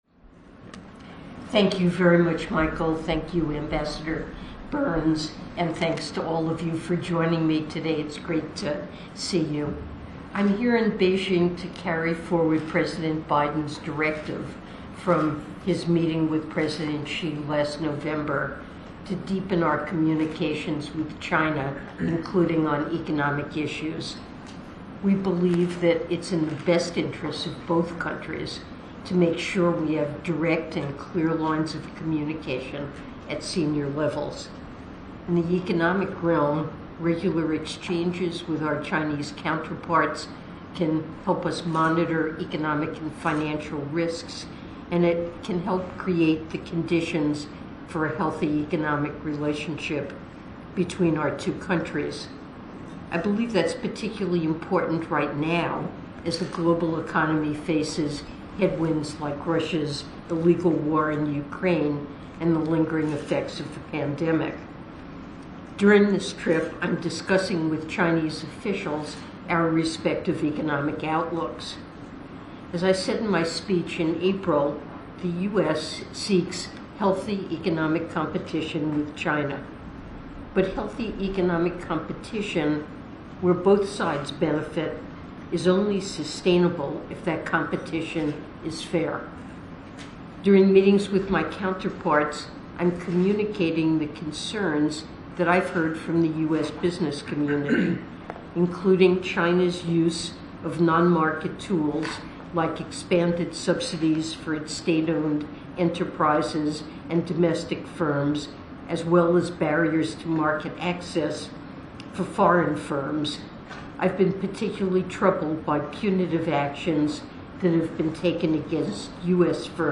Opening Remarks at Roundtable Discussion with U.S. Businesses Operating in the People’s Republic of China
delivered 7 July 2023, Beijing, China